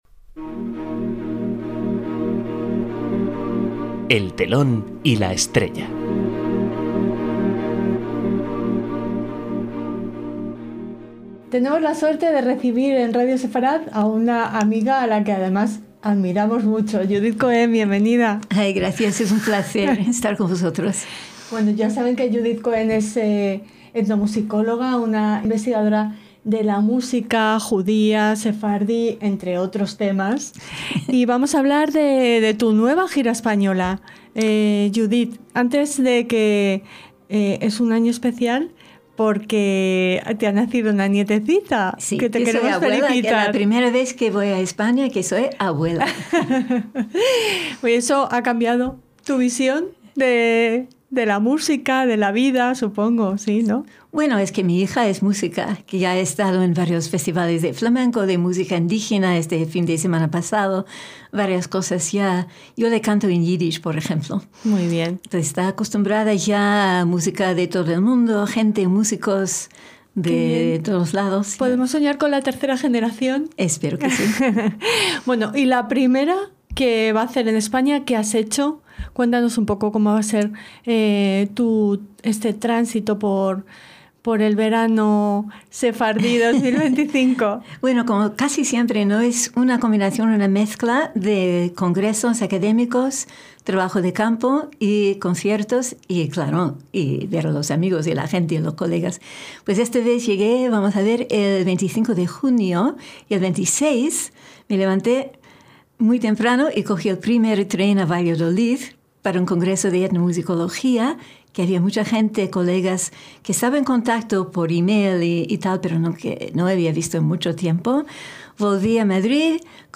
En esta entrevista, comparte vivencias, anécdotas, citas y proyectos que cruzan fronteras y siglos.